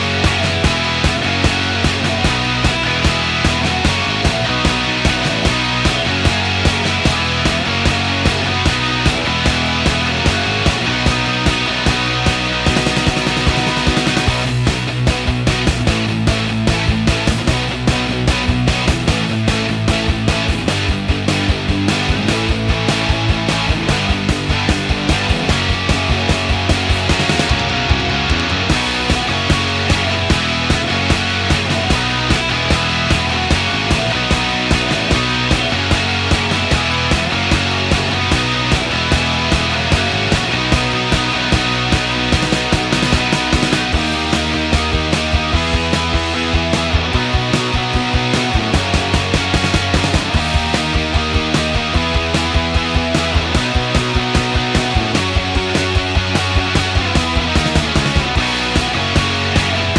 no Backing Vocals Punk 3:22 Buy £1.50